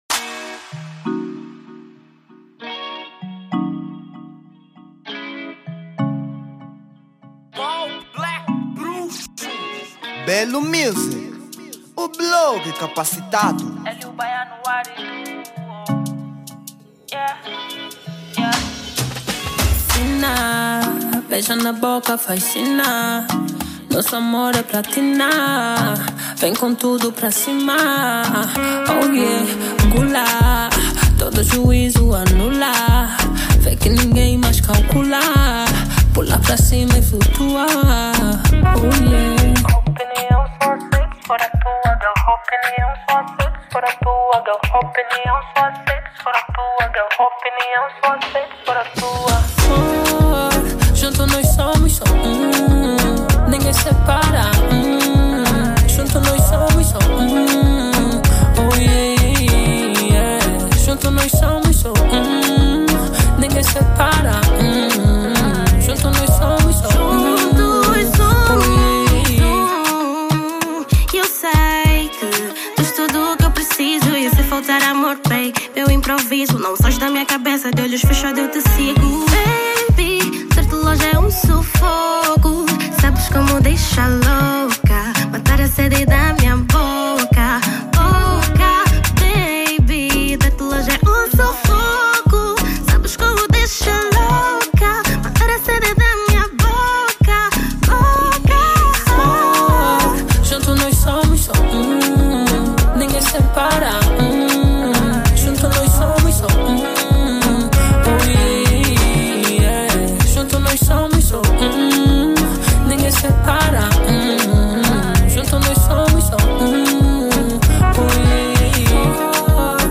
Género : Kizomba